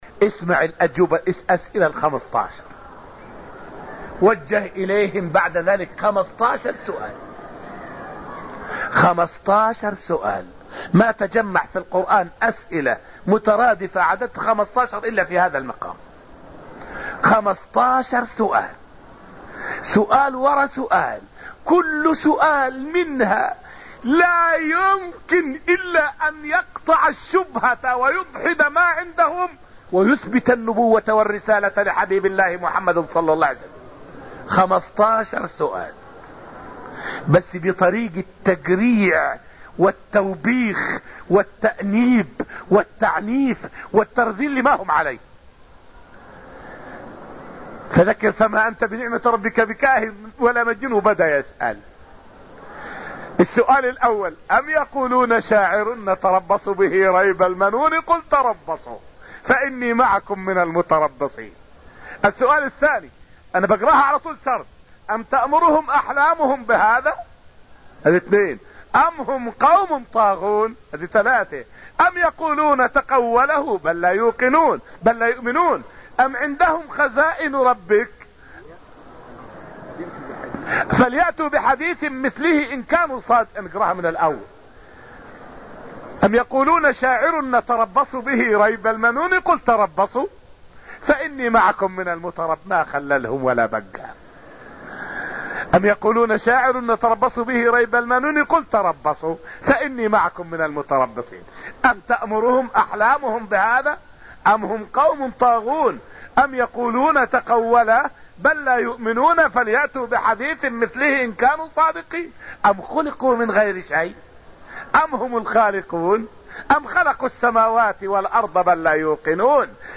فائدة من الدرس الخامس من دروس تفسير سورة الطور والتي ألقيت في المسجد النبوي الشريف حول الأسئلة القرآنية الموجهة للمكذبين بالنبوة.